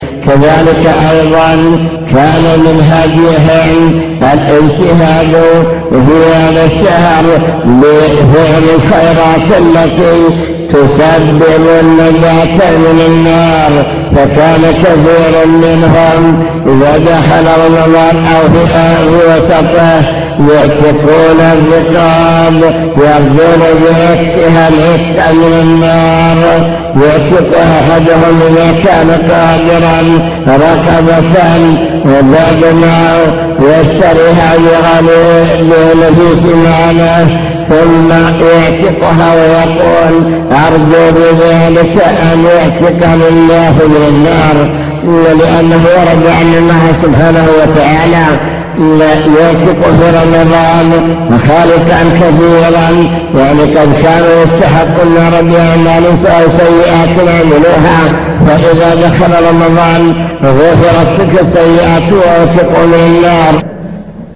المكتبة الصوتية  تسجيلات - محاضرات ودروس  مجموعة محاضرات ودروس عن رمضان هدي السلف الصالح في رمضان